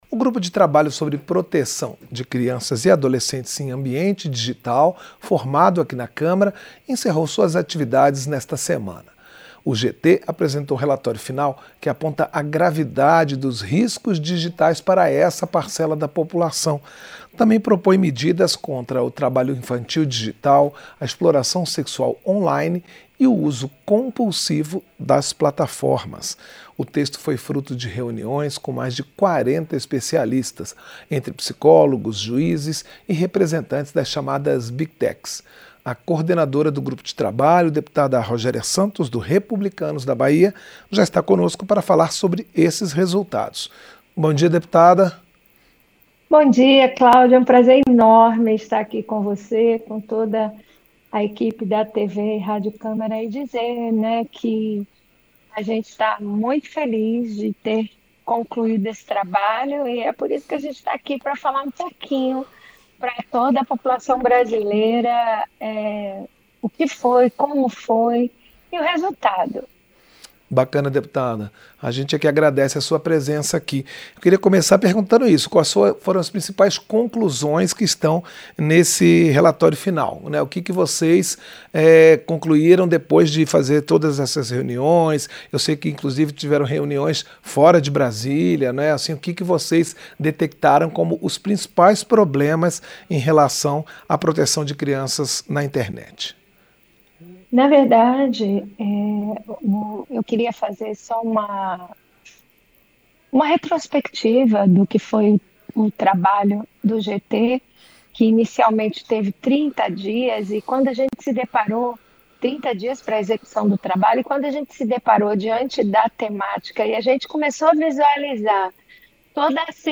Entrevista - Dep. Rogéria Santos (Rep-BA)